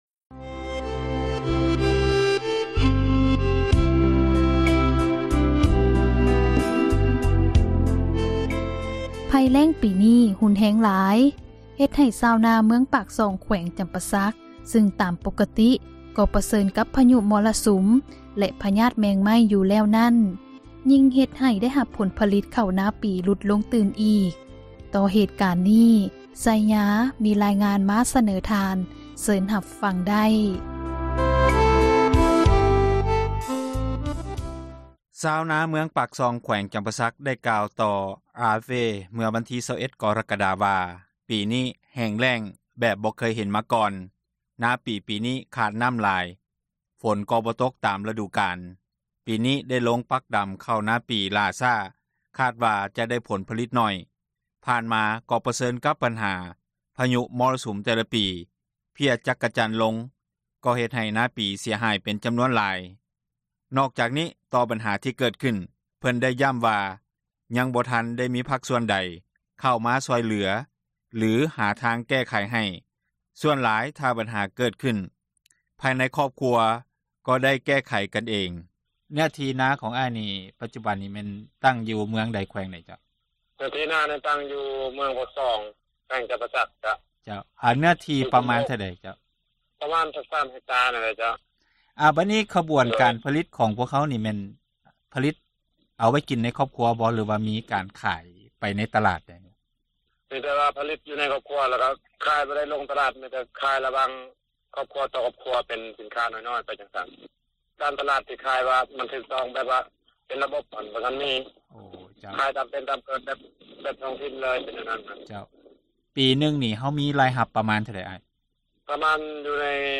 ສຳພາດ....